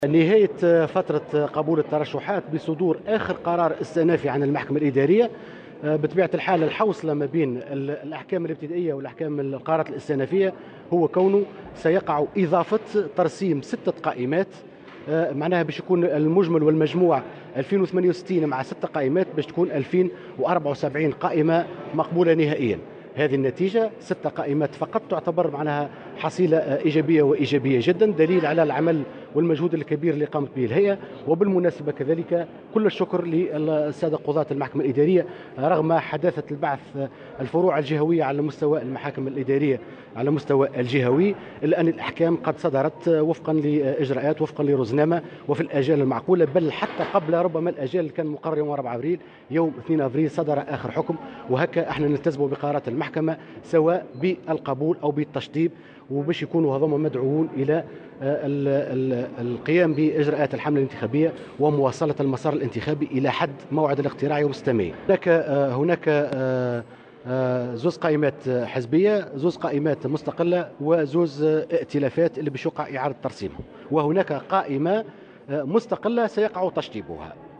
وأوضح رئيس هيئة الانتخابات محمد التليلي المنصري في تصريح لمراسلة "الجوهرة أف أم" أنه تم إعادة ادراج 6 قائمات (2 قائمات حزبية و2 قائمات ائتلافية و2 قائمات مستقلة) بعد استكمال البت في النزاعات على المستوين الابتدائي والاستئنافي.